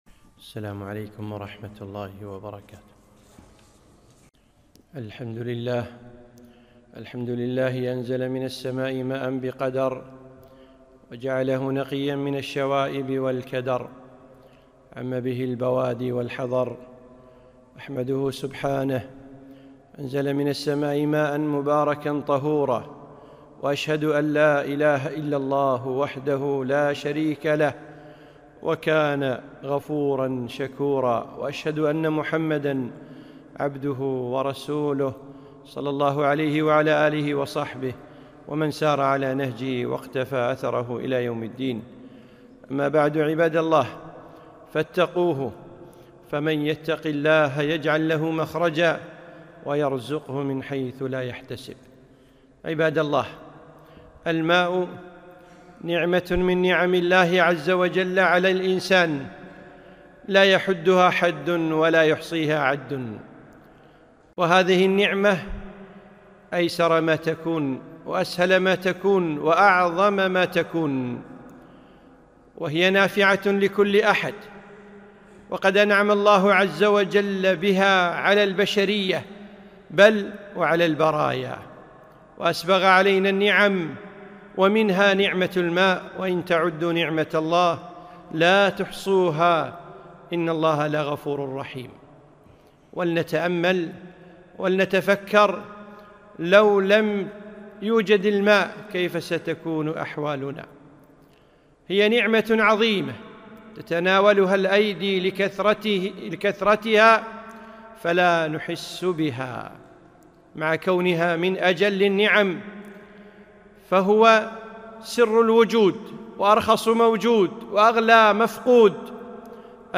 خطبة - الماء